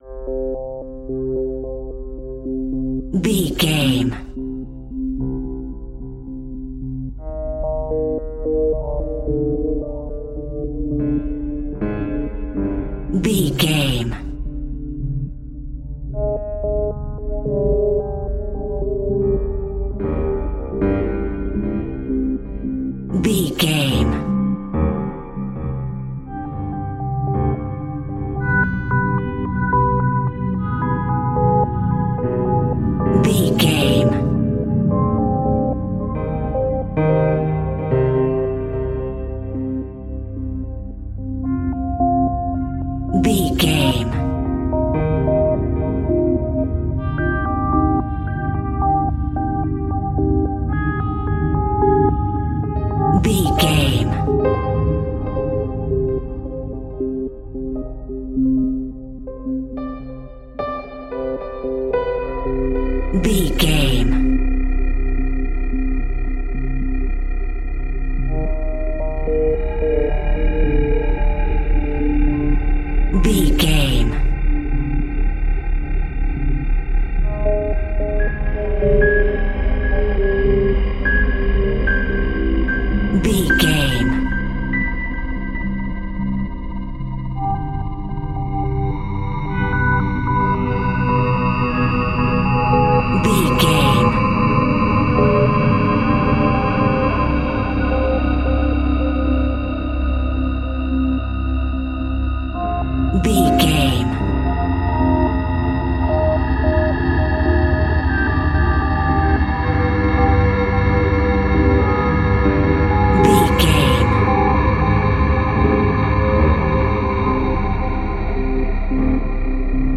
In-crescendo
Thriller
Aeolian/Minor
ominous
suspense
eerie
chilling
horror music
Horror Pads
horror piano
Horror Synths